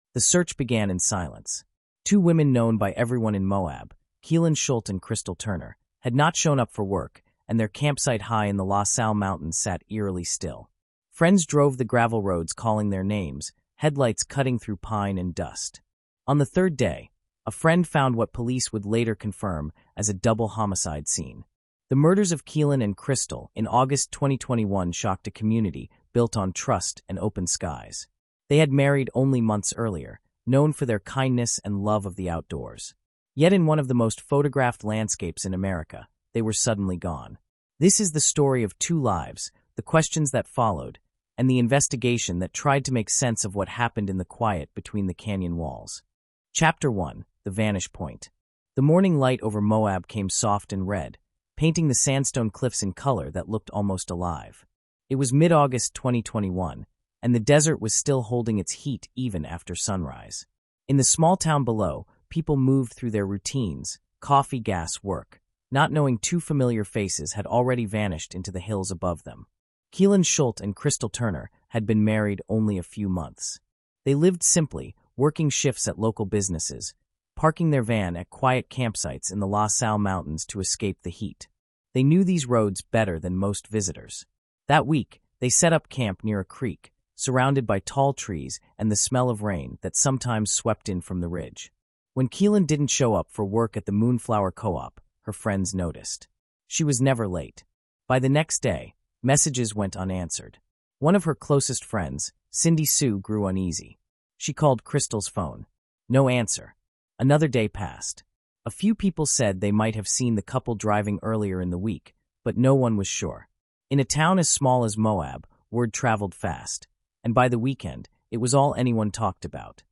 Told in a cinematic, forensic tone, the narrative moves from the moment their friends realize something is wrong to the slow, methodical investigation that exposes fear, loss, and the fragile line between freedom and danger in America’s wilderness.